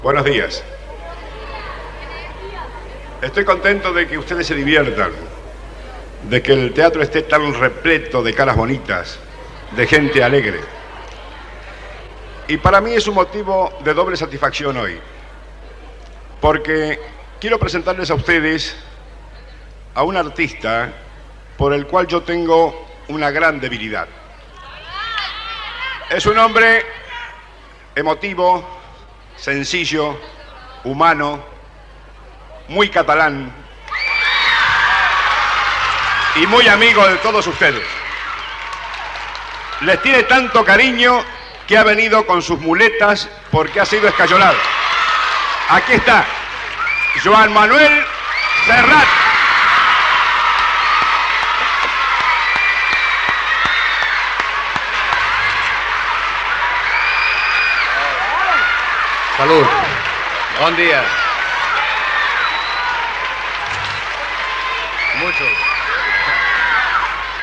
Festival anual del programa des del Teatre Español. Presentació de Joan Manuel Serrat.
Musical